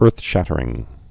(ûrthshătər-ĭng)